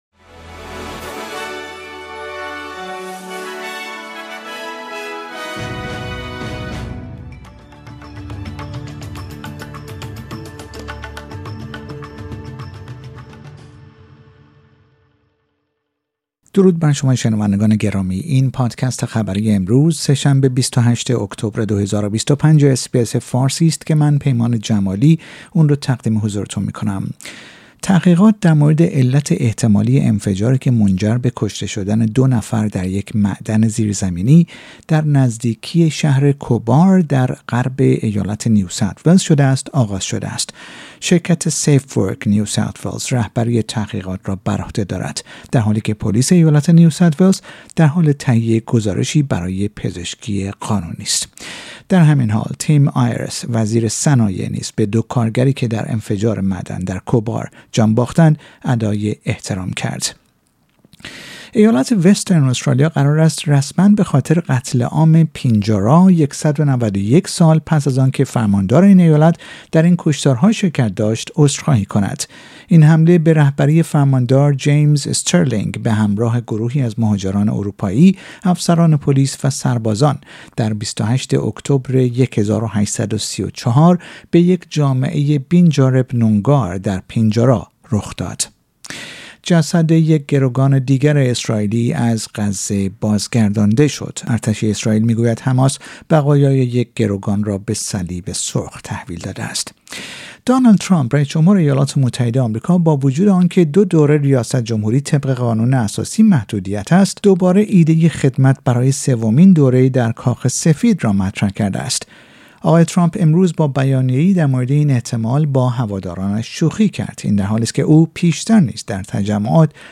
در این پادکست خبری مهمترین اخبار روز سه شنبه ۲۸ اکتبر ارائه شده است.